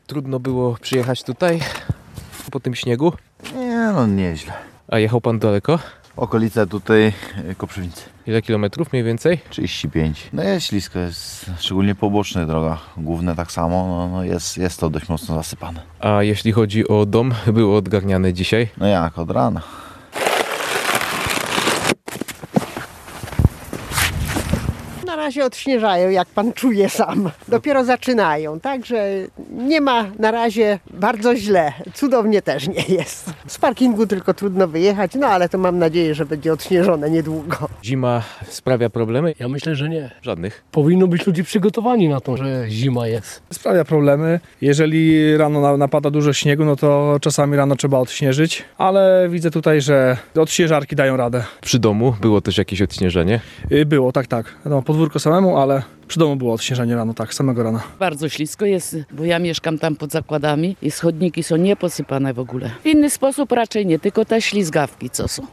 gdzie sprawdzał reakcje na warunki pogodowe zarówno okolicznych mieszkańców, jak i przyjezdnych: